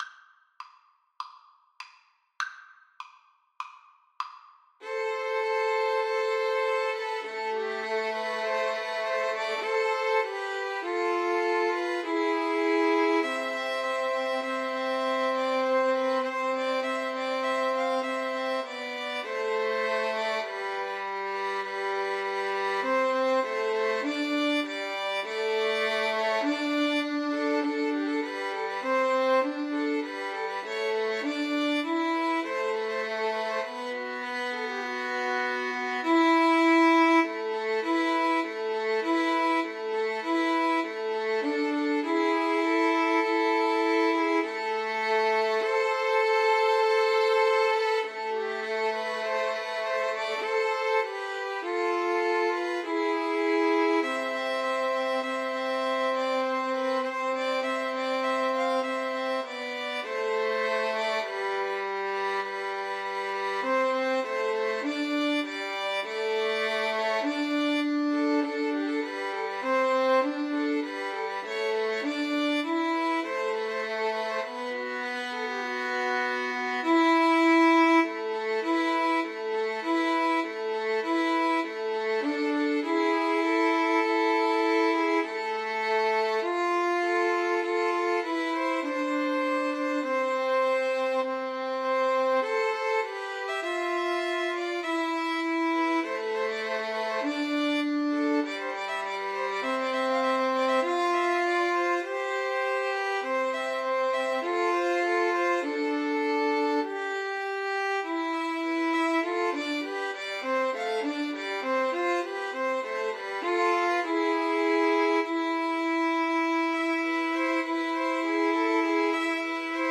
Free Sheet music for Violin Trio
[Moderato]
Classical (View more Classical Violin Trio Music)